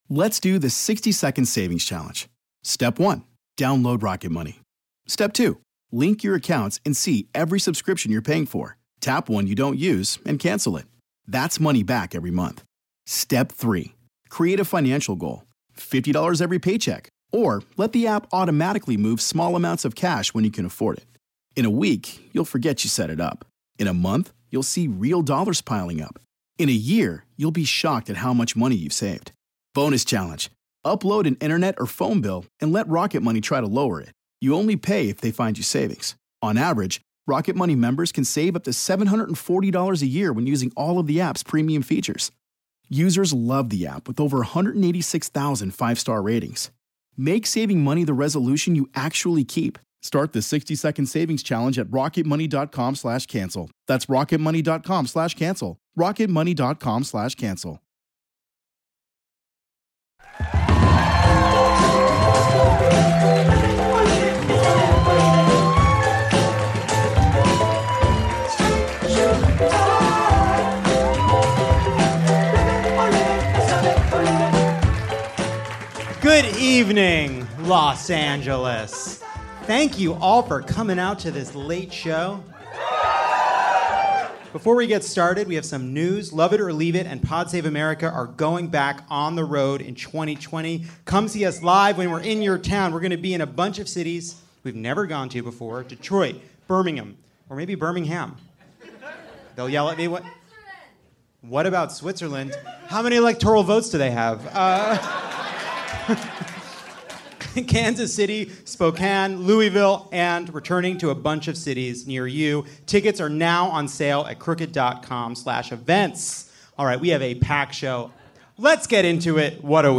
And Deval Patrick joins Jon on stage to face the Queen for a Day challenge.